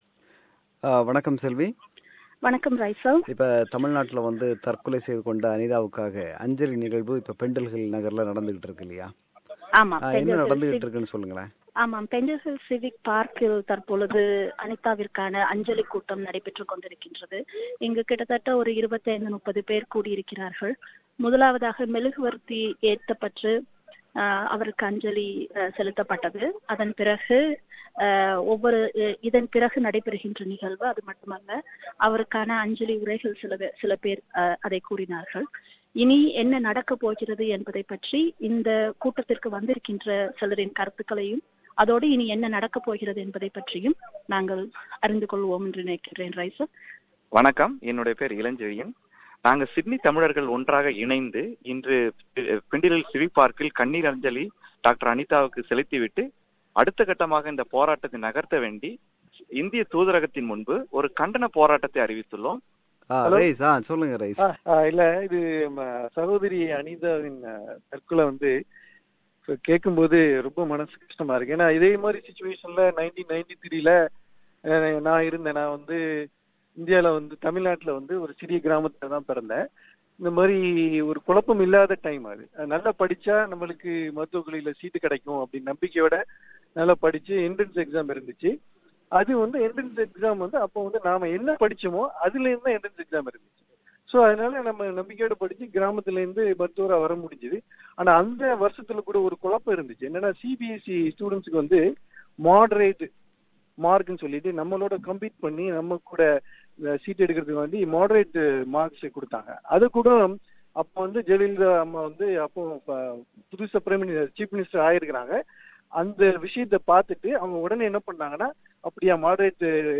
our Producer filed this report.